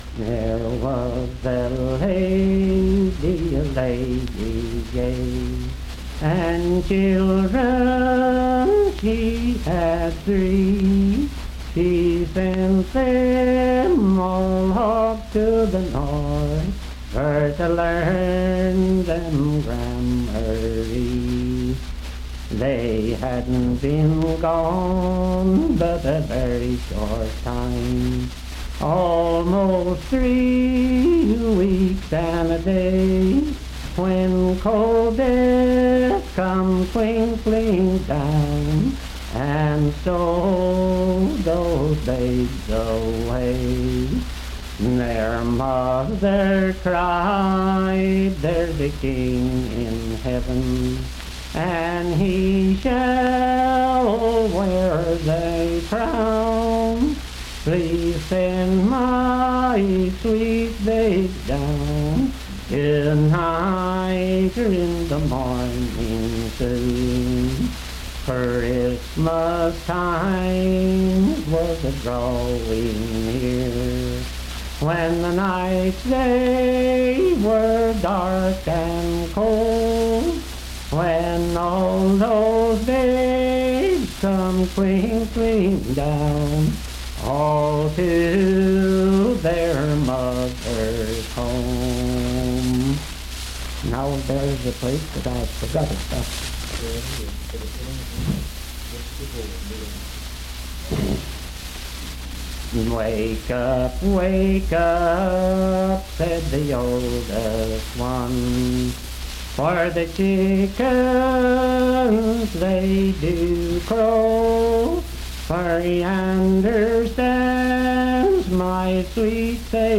Unaccompanied vocal music
Verse-refrain 7(4).
Voice (sung)
Randolph County (W. Va.)